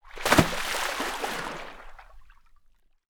Water_62.wav